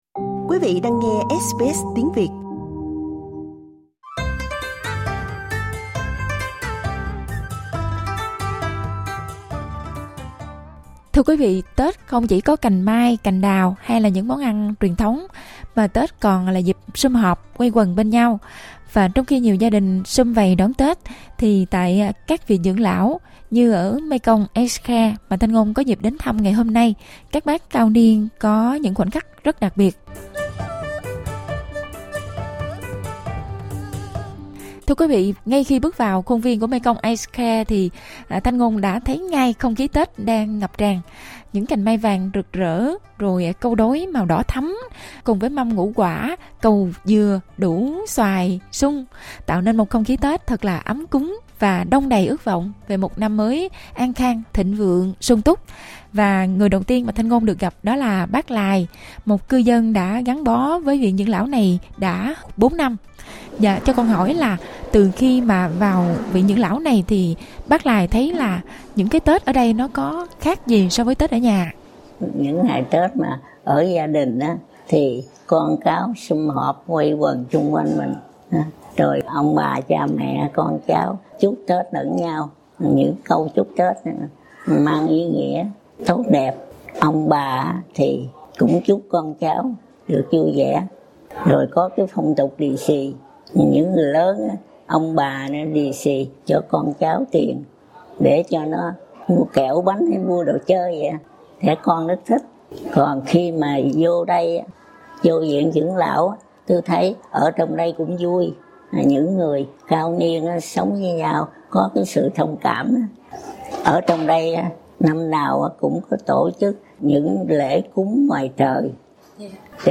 Với nhiều bác cao niên người Việt đang sống trong các viện dưỡng lão ở Úc, ngày Tết có thể thiếu vắng người thân, nhưng không vì thế mà thiếu đi những nét văn hóa truyền thống. SBS Tiếng Việt đã đến thăm viện dưỡng lão Mekong Keilor East ở Victoria, nơi các nhân viên cùng nhau mang đến một cái Tết ấm áp cho các bác cao niên.